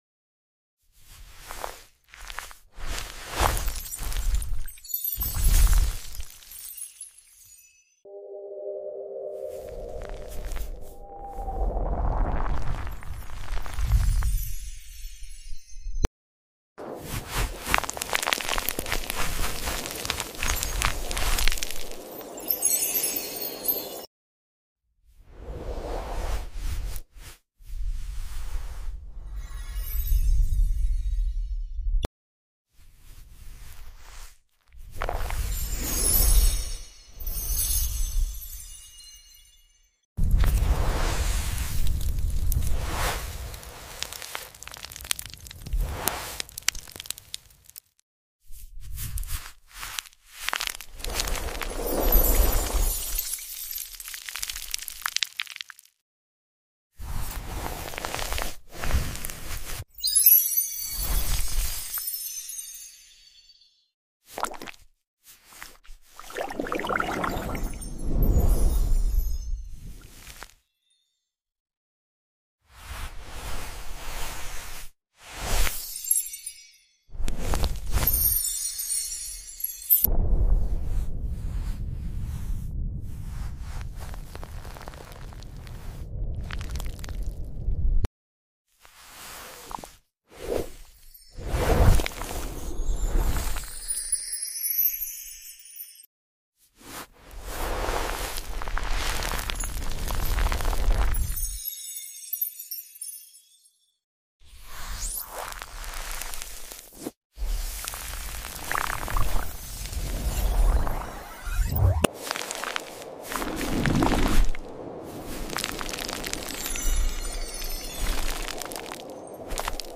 21 Silky Smooth Brush Strokes. sound effects free download